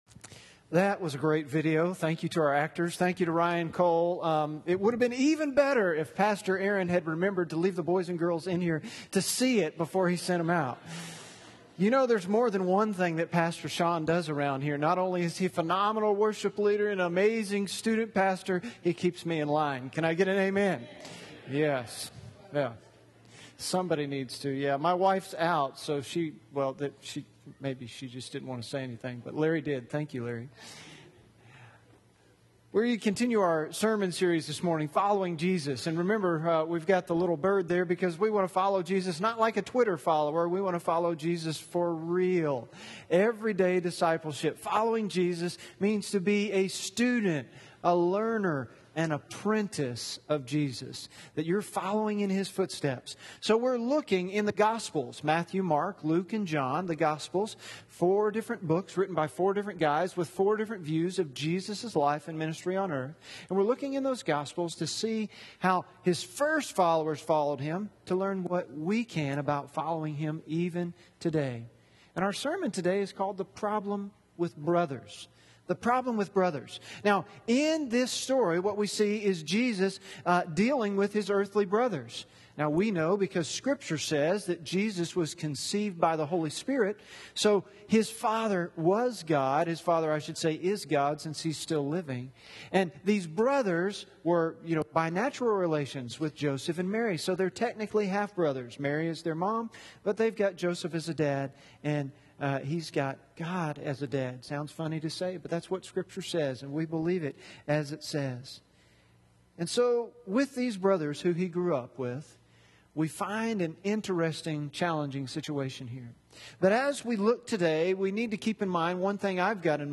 John 7:1-13 Sermon notes on YouVersion Following Jesus: The Problem with Brothers